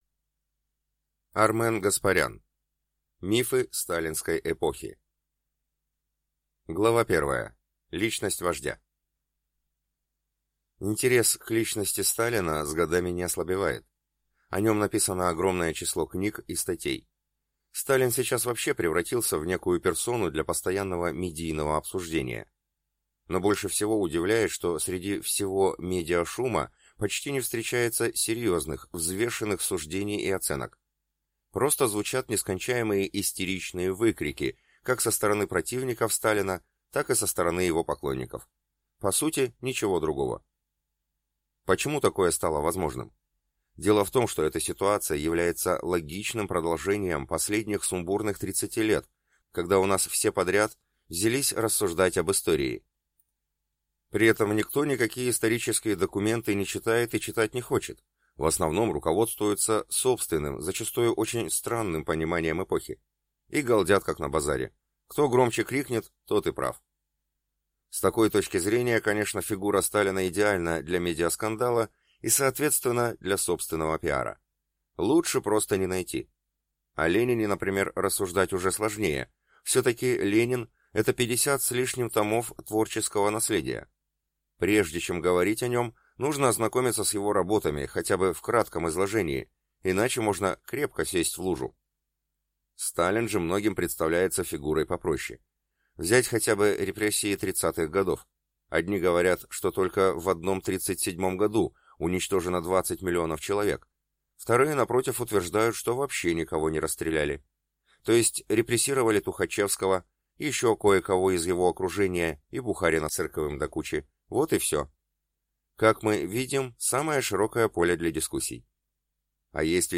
Аудиокнига Мифы сталинской эпохи | Библиотека аудиокниг